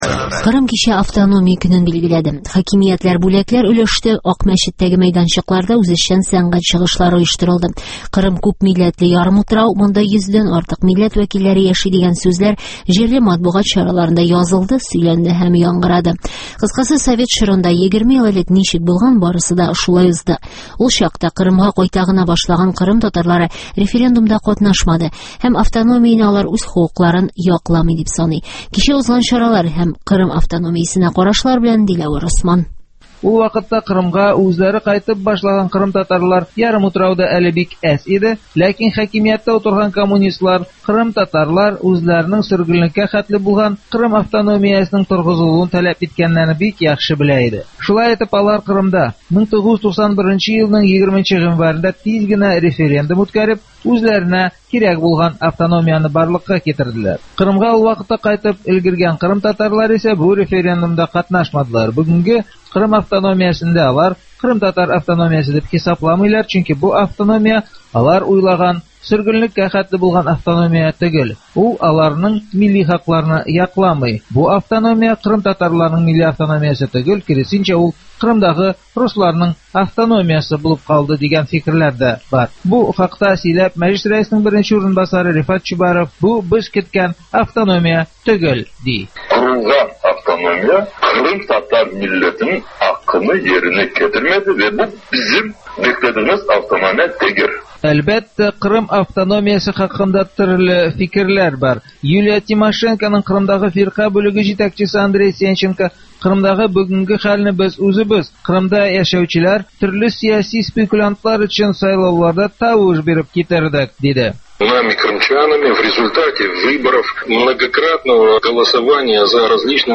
Кырым Автономиясенең 20 еллыгы турында репортаж